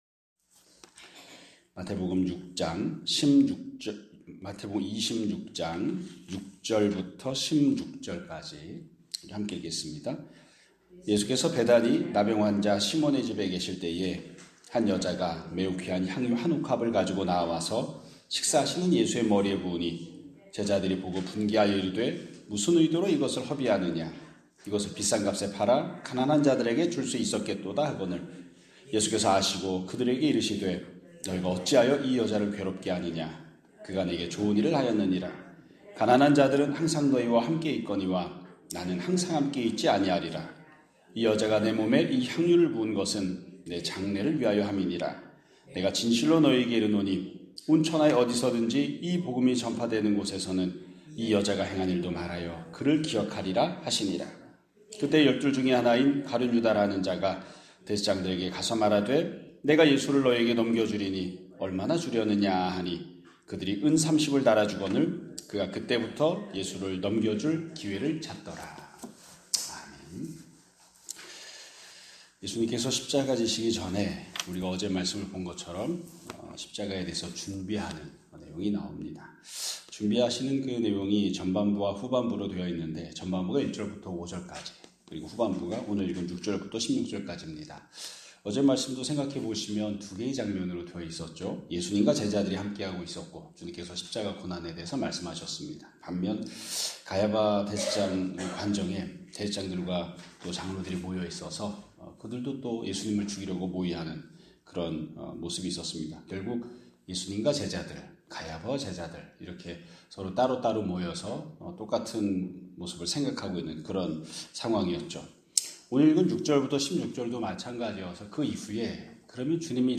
2026년 3월 27일 (금요일) <아침예배> 설교입니다.